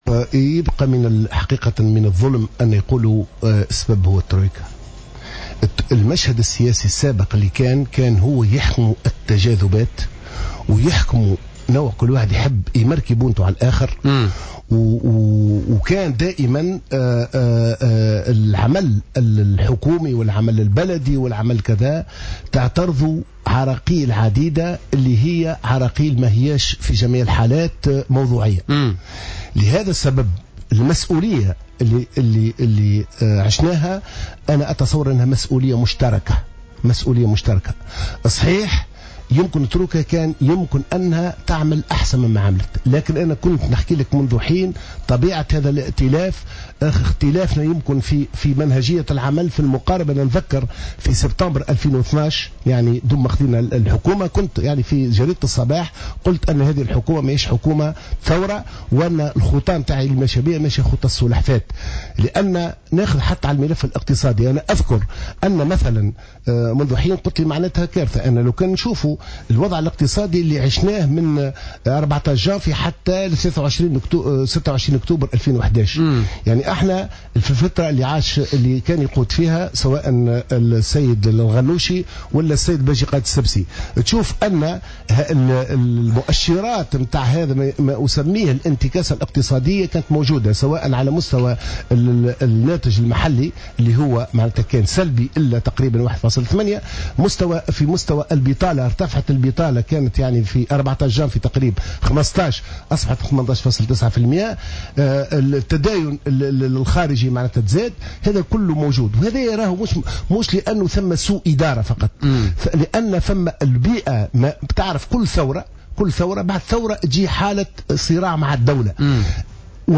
اعتبر عبد الوهاب معطر الوزير السابق والقيادي في المؤتمر من أجل الجمهورية ضيف بوليتيكا على جوهرة أف أم أن مؤشرات الانتكاسة الاقتصادية انطلقت منذ تولي محمد الغنوشي والباجي قائد السبسي لدواليب الحكم عقب الثورة، مؤكدا أنه من الظلم أن يُردّ التراجع الاقتصادي والاجتماعي في تونس إلى حكومتي الترويكا.